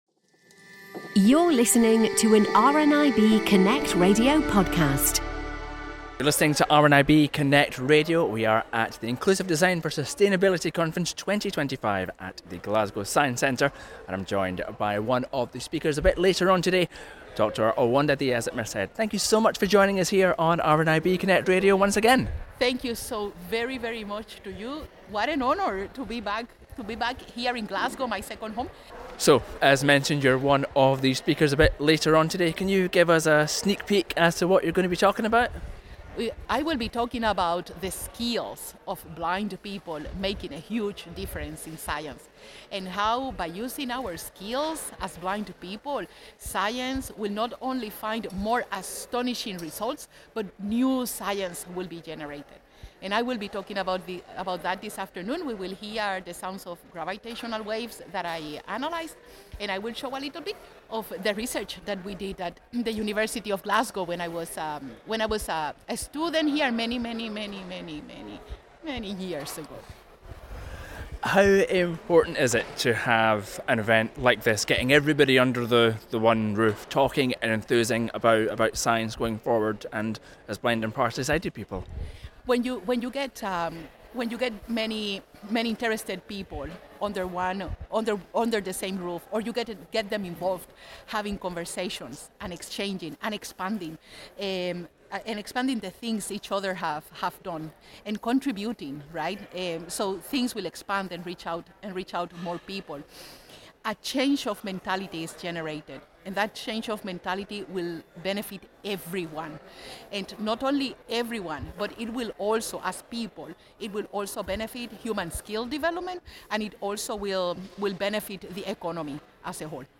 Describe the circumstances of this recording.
More now from the IDS Conference at the Glasgow Science Centre